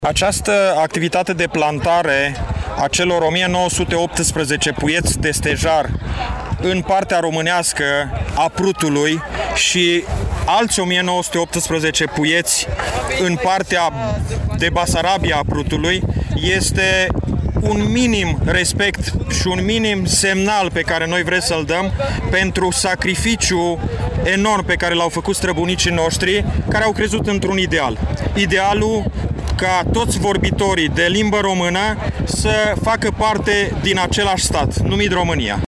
La acţiune participă şi ministrul apelor şi pădurilor din România, Ioan Deneş: